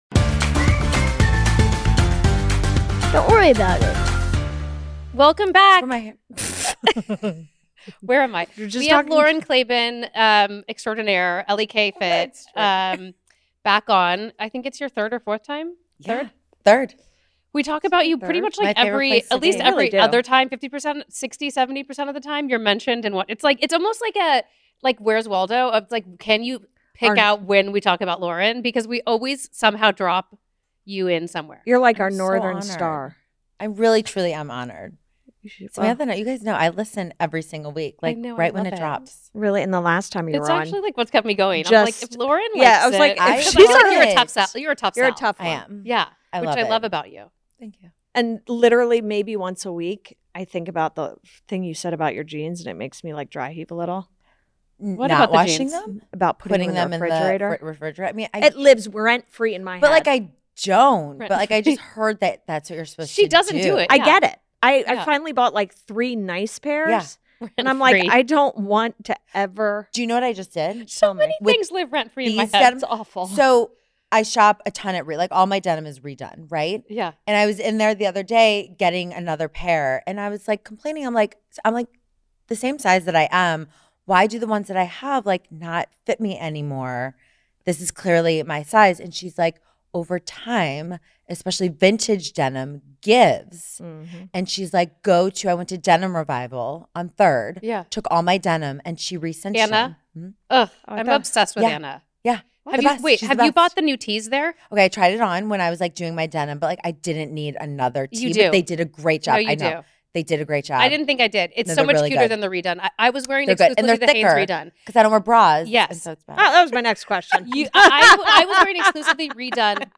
It’s a feel-good episode with lots of laughs and honest moments.